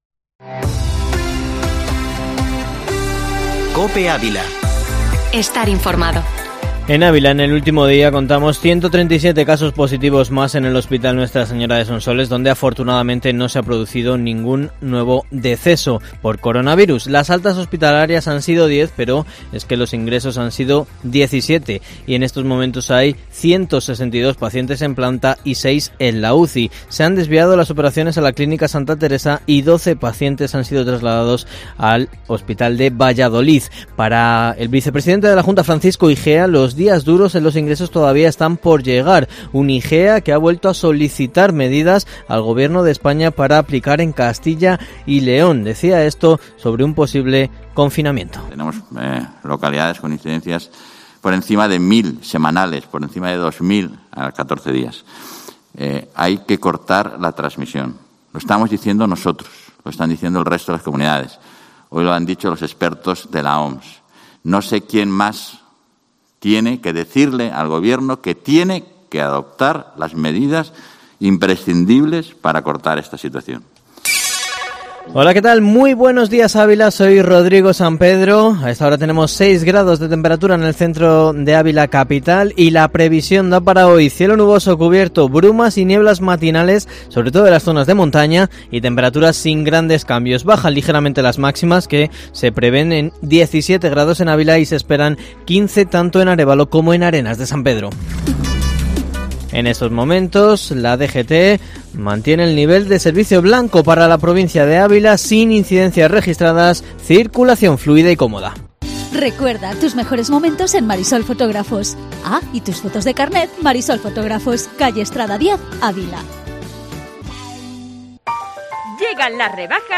Informativo matinal Herrera en COPE Ávila 29/01/2021